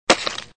44_hitSound.mp3